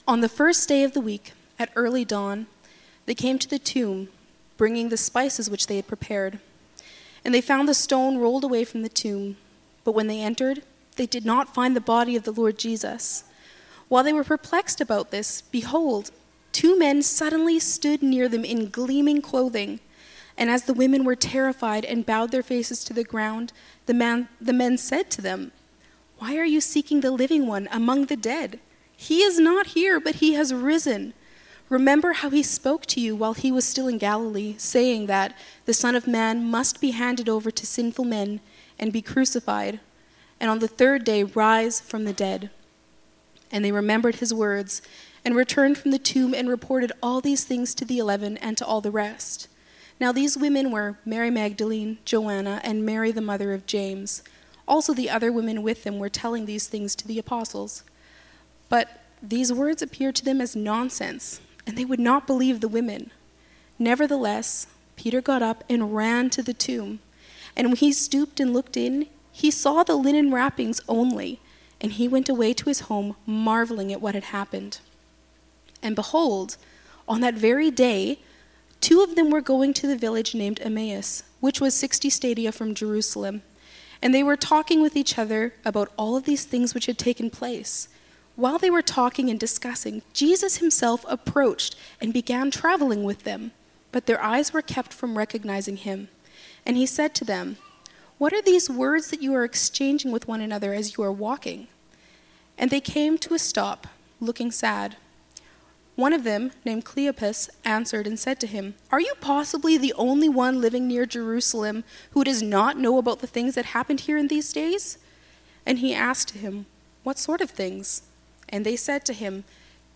Luke 24:1-6 Service Type: Sunday Morning Topics: Easter « Because You Have Heard